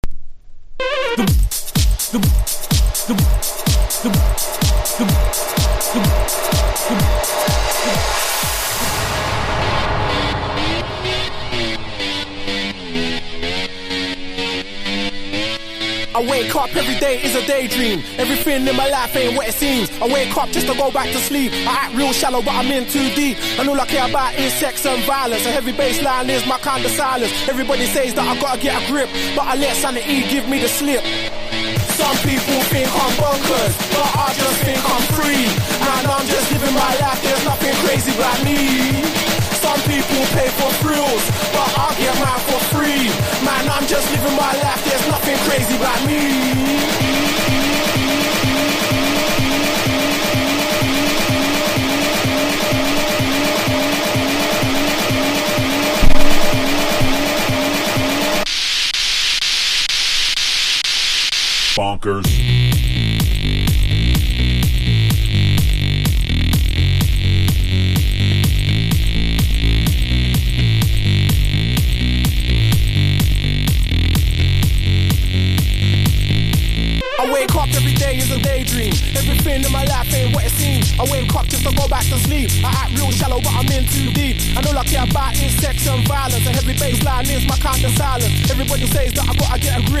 BREAK BEATS / BIG BEAT